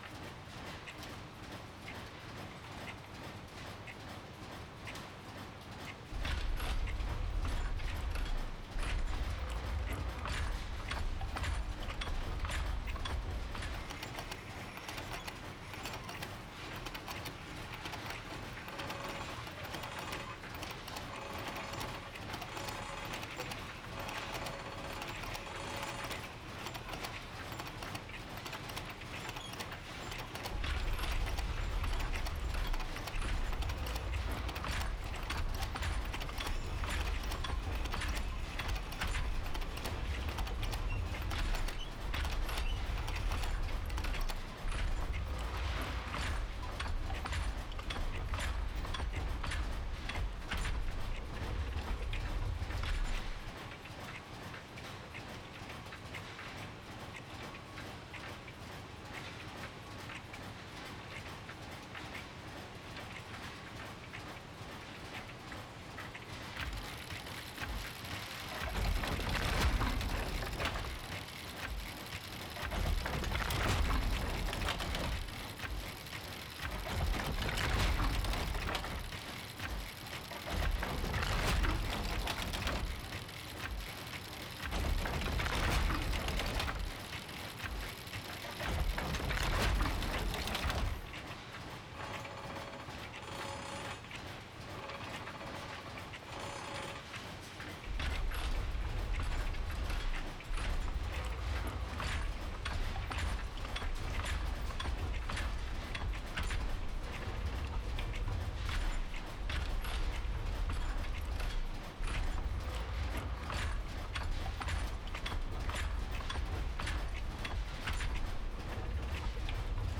Steampunk Factory.ogg